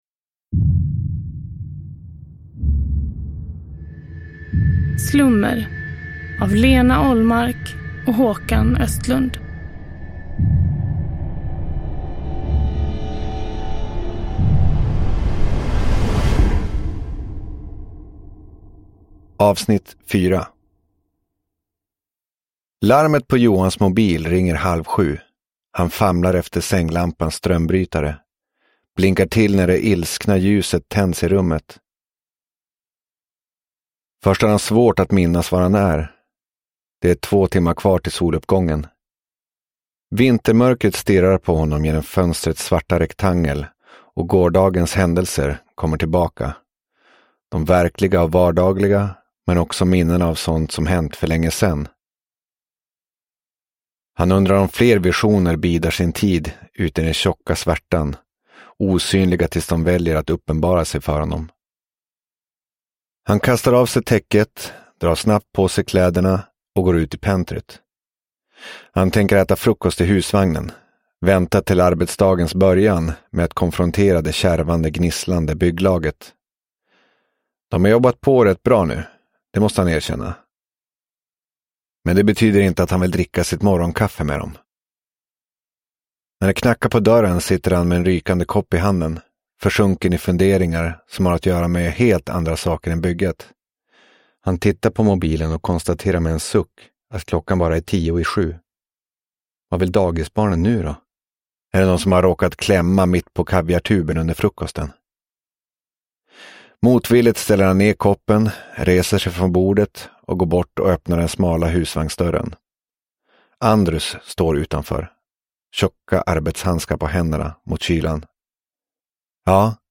Slummer - Del 4 – Ljudbok – Laddas ner